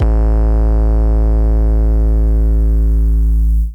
808 Kick 9_DN.wav